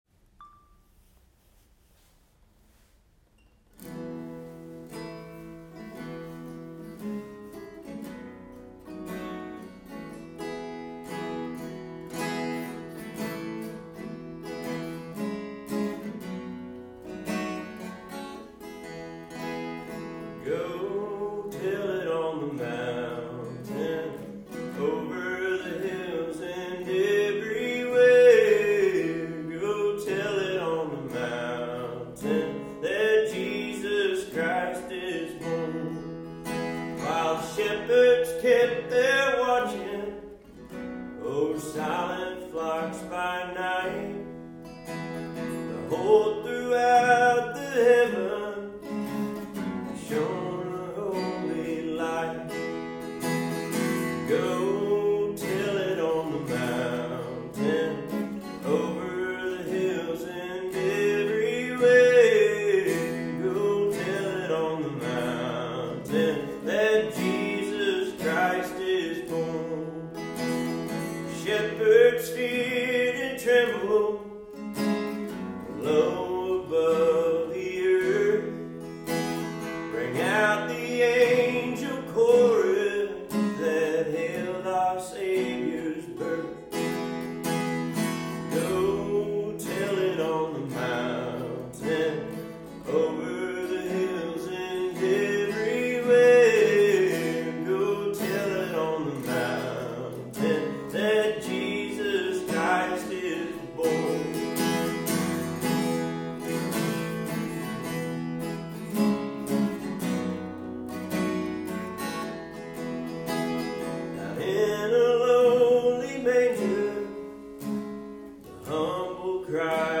These music sessions were recorded in the Chapel on the Dunes.